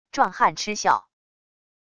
壮汉嗤笑wav音频